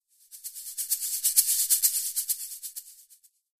Music Effect; Rock Snare Drum Flam Hit.